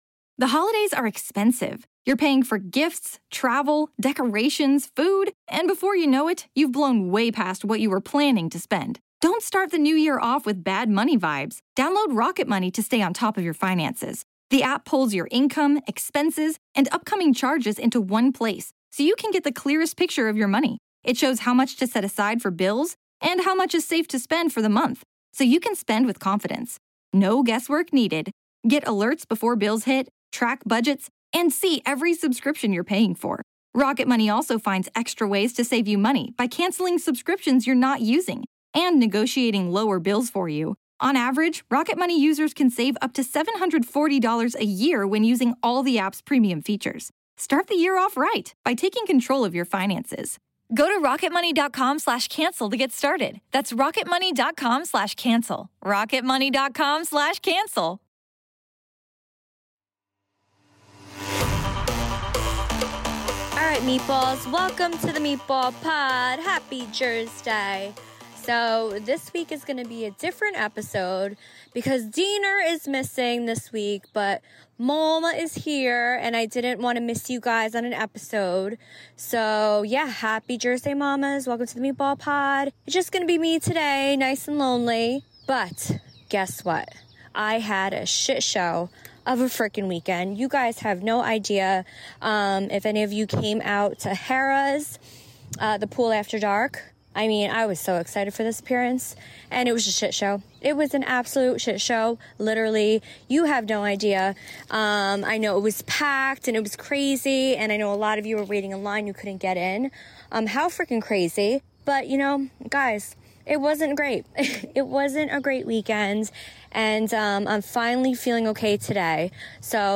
This week, Snooki catches us up on her hectic weekend phone call style from a stroll around her neighborhood. Bad stomachache story ahead!